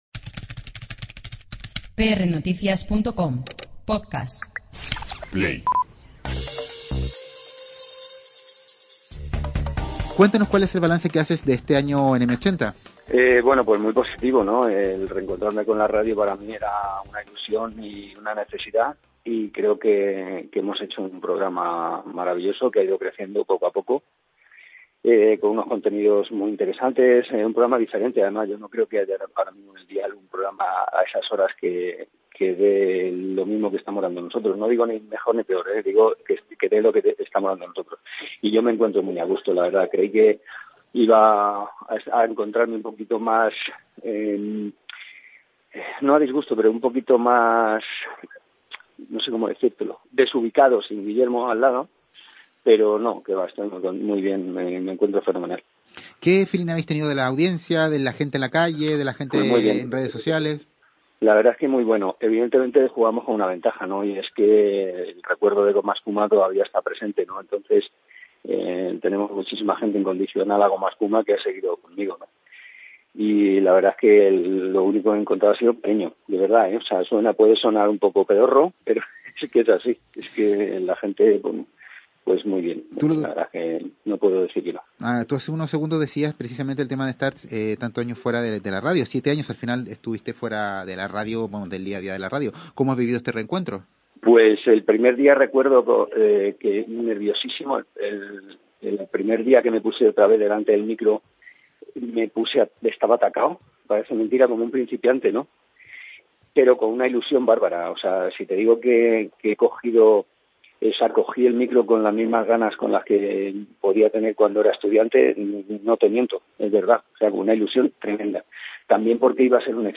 Juan Luis Cano ha cumplido un año al frente de Ya veremos de M80, el magazine que le permitió volver al día a día de la Radio después de siete años. En entrevista con prnoticias el presentador hace un balance positivo de estos doce meses muy confiado e ilusionado con su apuesta: “no hay ningún programa a esas horas (de 20 a 22 hrs) que dé lo mismo que estamos dando nosotros”.